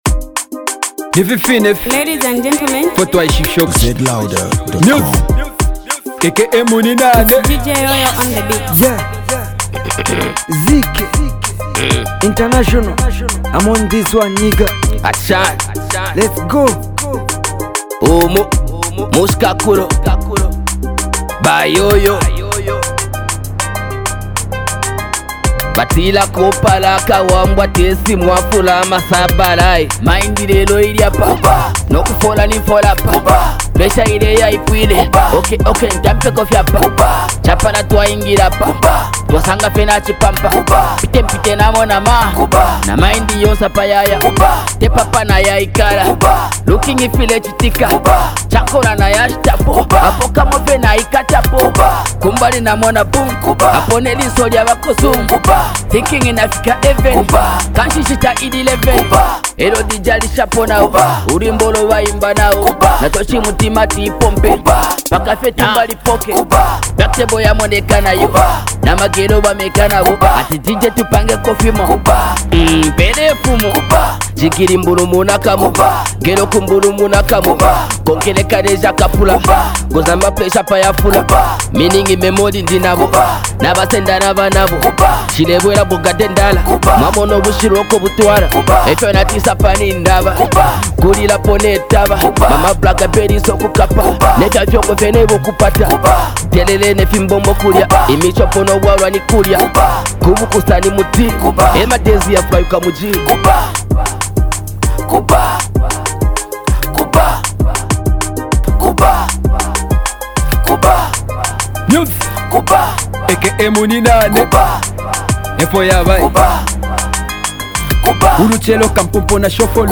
dope december banger